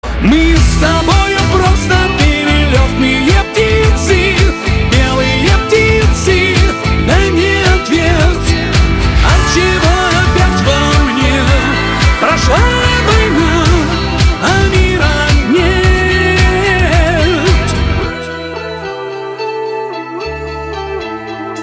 поп
мужской вокал
эстрадные
романтические
русская эстрада